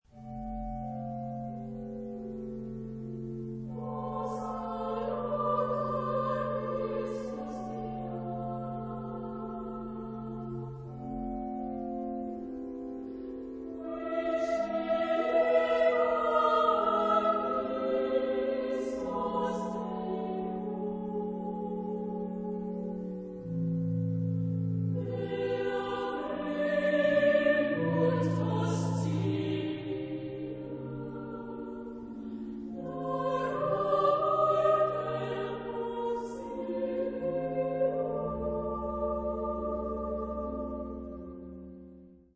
Epoque: 20th century
Genre-Style-Form: Motet ; Sacred
Type of Choir: SA  (2 children OR women voices )
Instruments: Organ (1)
Tonality: atonal